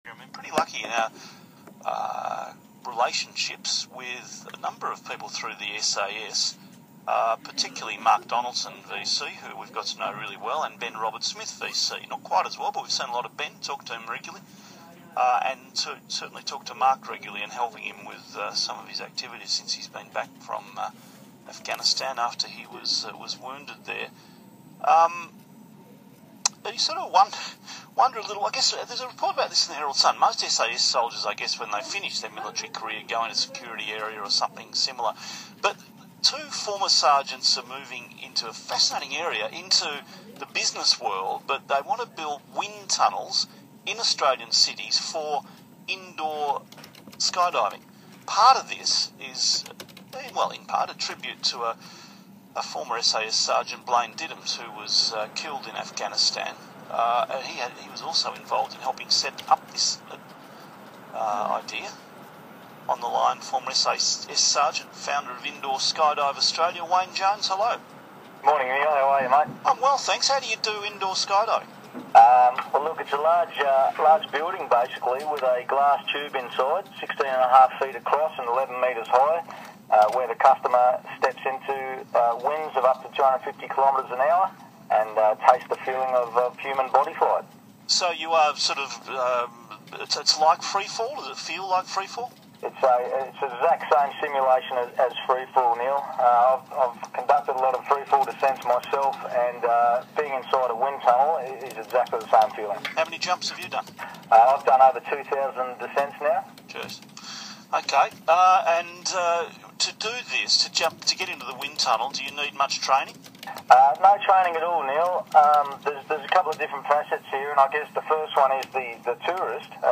ISAG Neil Mitchell interview 26 Nov – 3AW
ISAG-Neil-Mitchell-interview-26-Nov-3AW.mp3